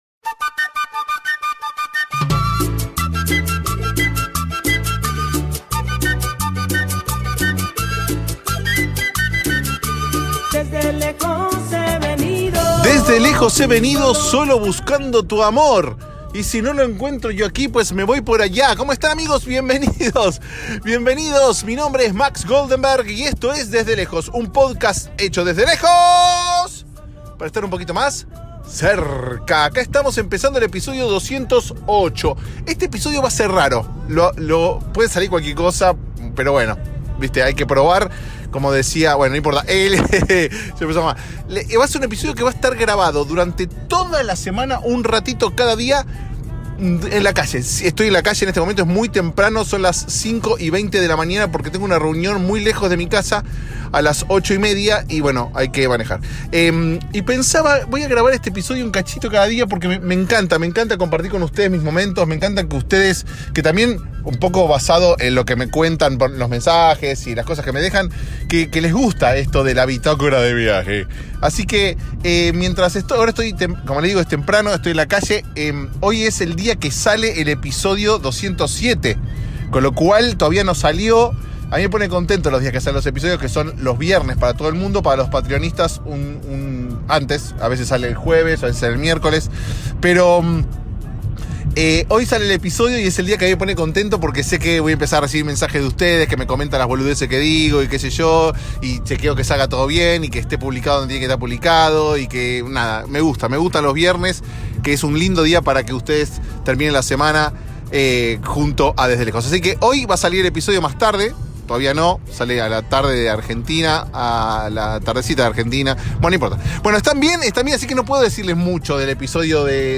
No estoy seguro de que el término "alog" exista pero bueno... si vlog es video blog, alog vendría a ser un audio blog.
Grabado durante 1 semana totalmente en exteriores, fue una experiencia extraña pero divertida.